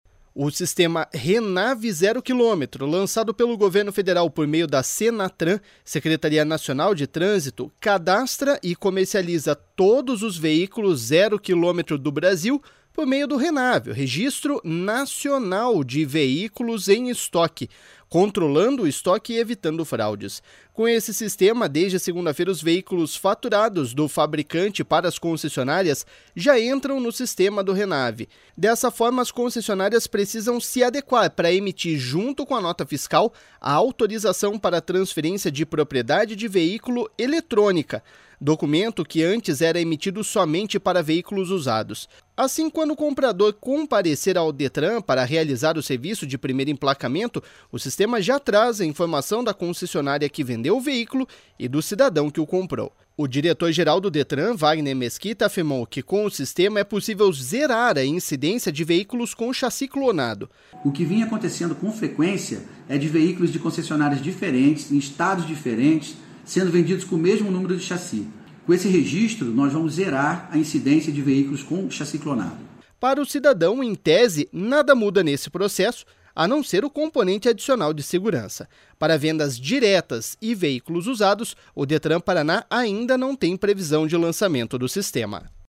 //SONORA WAGNER MESQUITA//